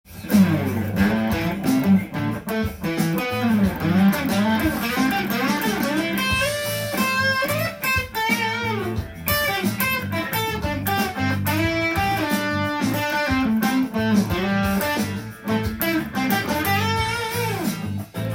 【5度で動くマイナーペンタトニックスケール】
ギターソロっぽく弾いてみました
ブルース風というよりも知的な雰囲気をする